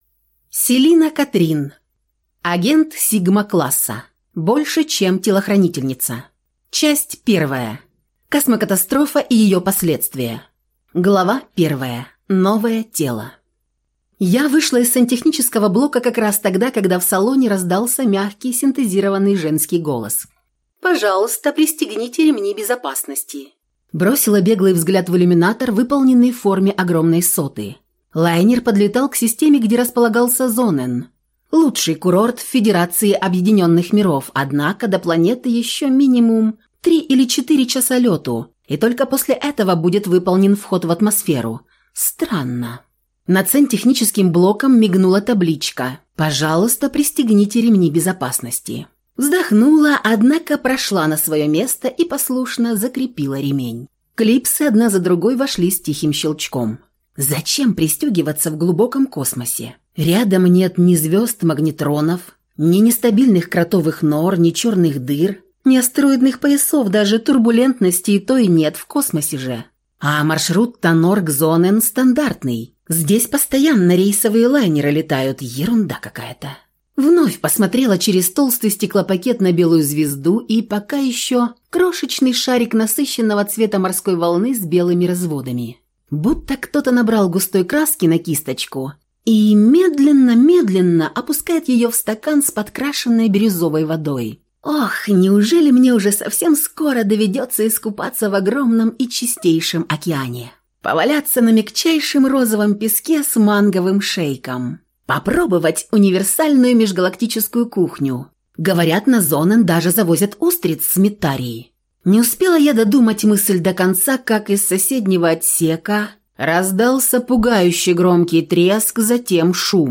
Аудиокнига Агент сигма-класса. Больше, чем телохранительница | Библиотека аудиокниг